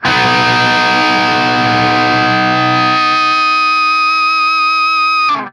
TRIAD B   -R.wav